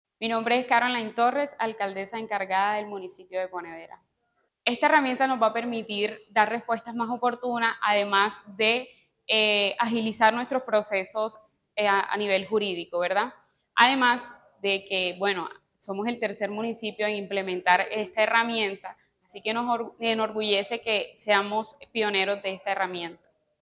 Caroline-Torres-alcaldesa-encargada-de-Ponedera.mp3